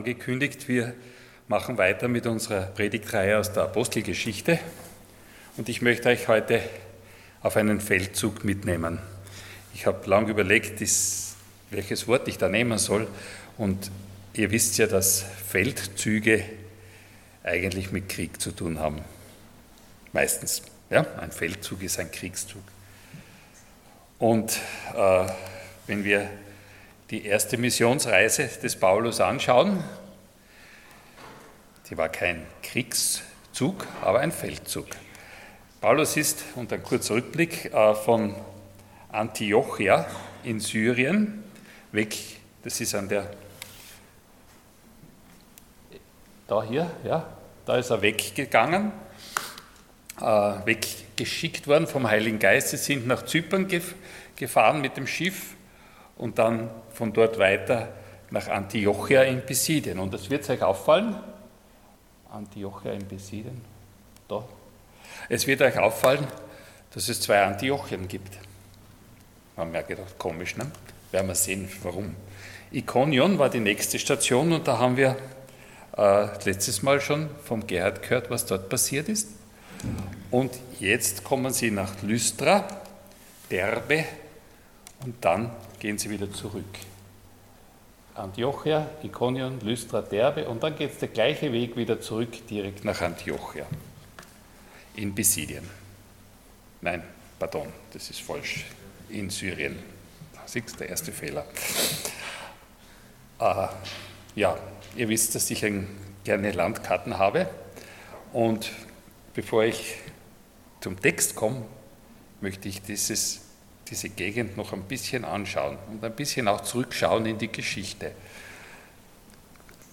Dienstart: Sonntag Morgen